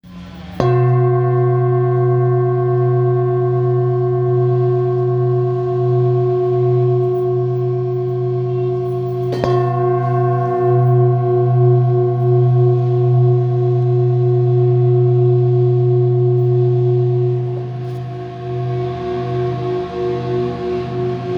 Singing Bowl, Buddhist Hand Beaten, with Fine Etching Carving, Chenrezig, Thangka Color Painted
Material Seven Bronze Metal
When played, the bowls produce a rich, harmonious sound that is said to stimulate the chakras and bring balance to the body's energy centers.